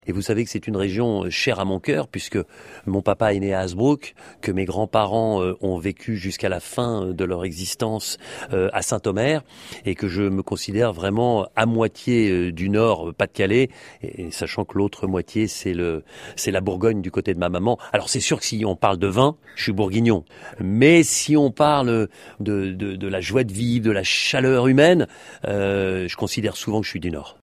Denis BROGNIART au micro